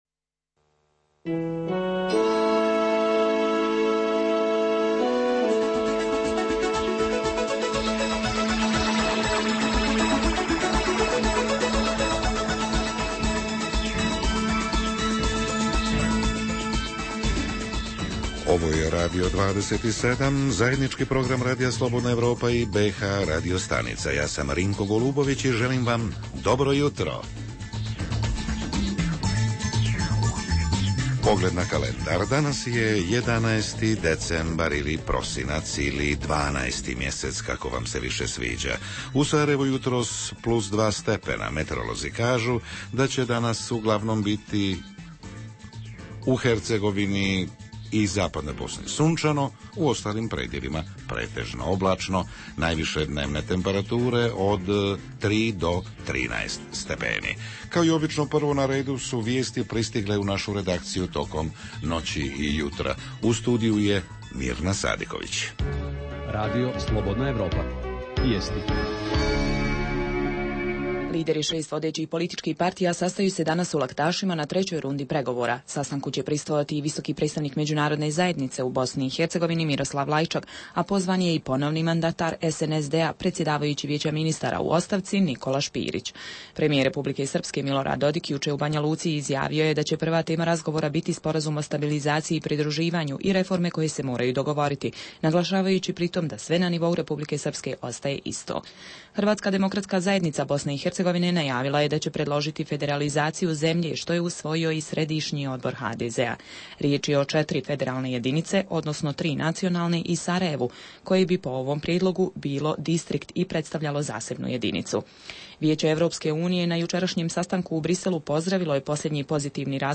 Jutarnji program za BiH koji se emituje uživo. Tema jutra je rad predškolskih ustanova, način finansiranja, broj osoblja u odnosu na broj djece, cijene, problemi ….
Redovni sadržaji jutarnjeg programa za BiH su i vijesti i muzika.